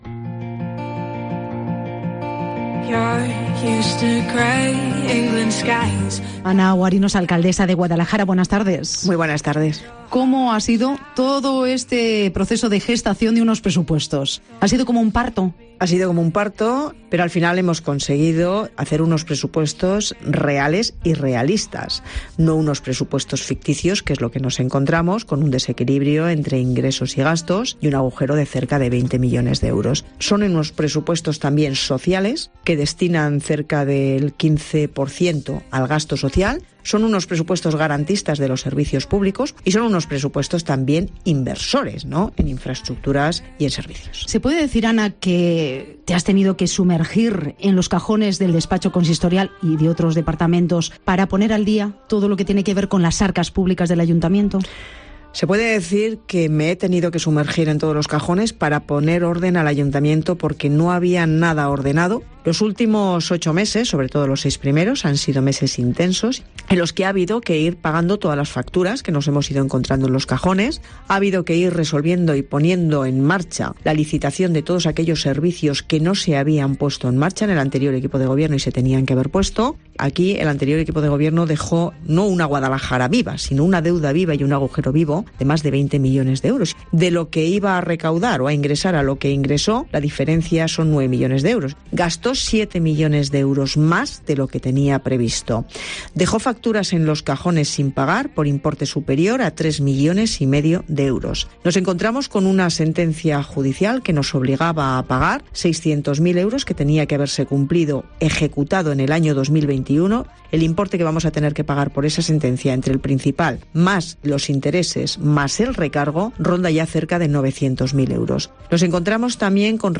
Tras la enmienda anunciada por el Grupo Socialista del Ayuntamiento de Guadalajara a los Presupuestos de 2024, la alcaldesa Ana Guarinos ha pasado por los micrófonos de Herrera en COPE en Guadalajara para defenderlos como "unos presupuestos reales y realistas; sociales, ya que destinan cerca del 15% al gasto social; garantistas de los servicios públicos, e inversores en infraestructuras y servicios".